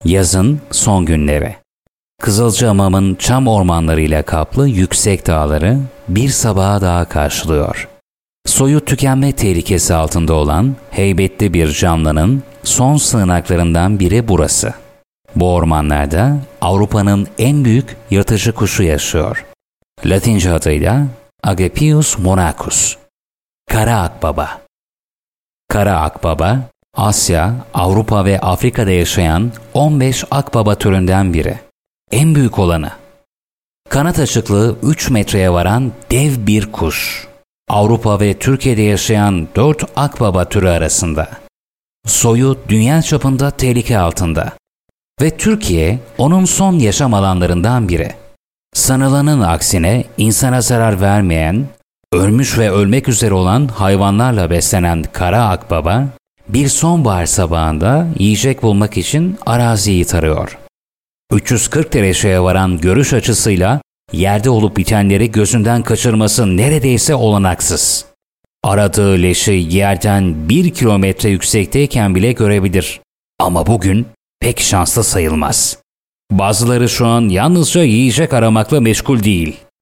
Turkish Voiceover Artist.
Sprechprobe: eLearning (Muttersprache):